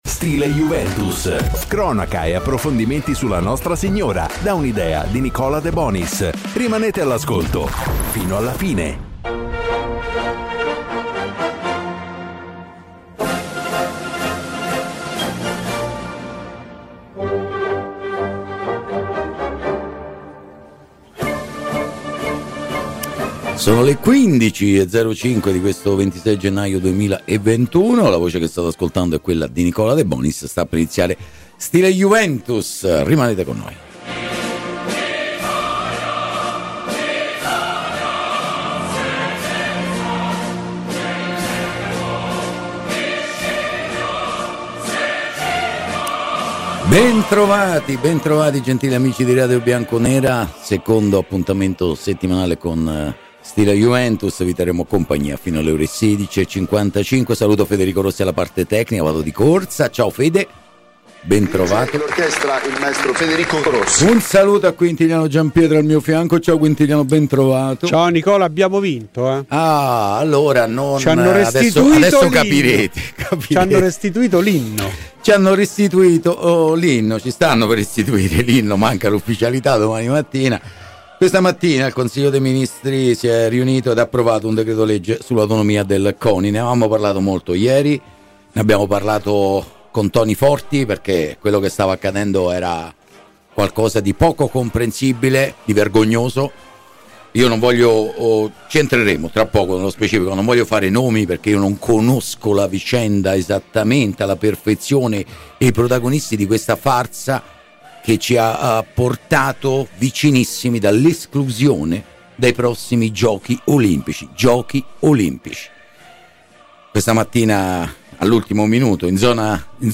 Ai microfoni di Radio Bianconera, nel corso di ‘Stile Juventus’, è intervenuto il campione olimpico Stefano Baldini: “La Juve di Pirlo?